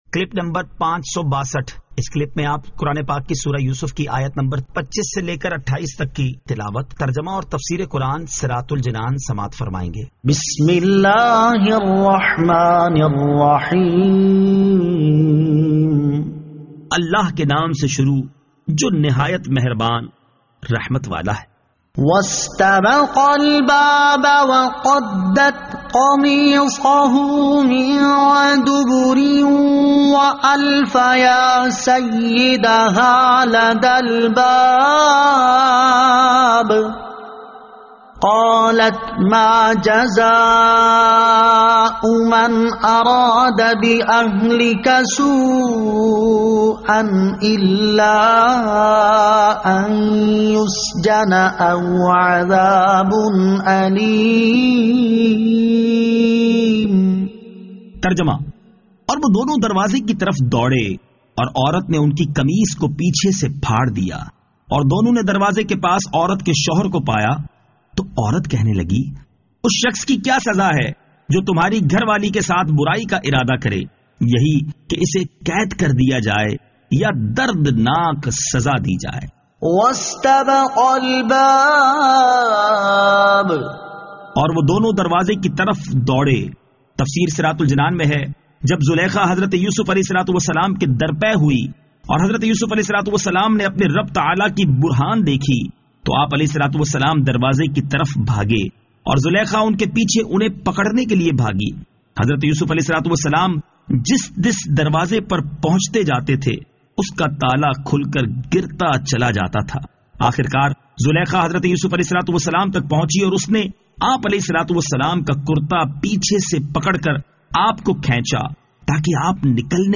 Surah Yusuf Ayat 25 To 28 Tilawat , Tarjama , Tafseer